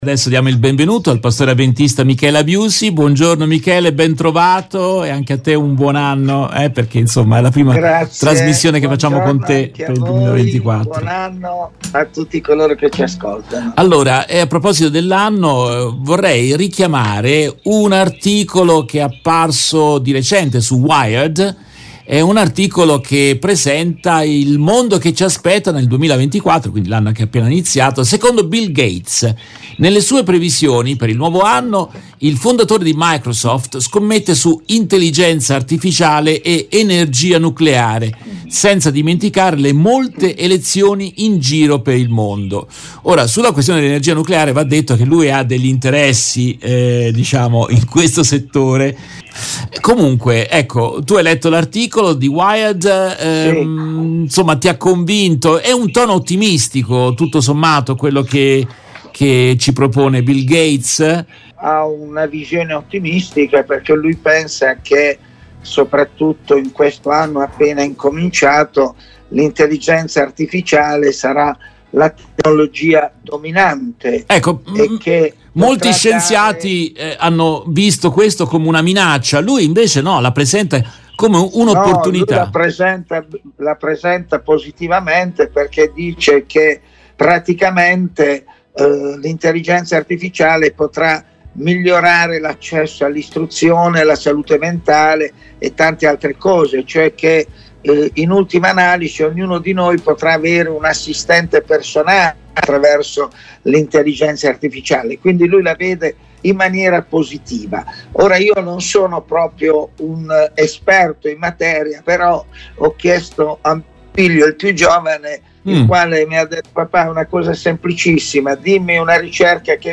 In questa intervista tratta dalla diretta RVS del 05 gennaio 2024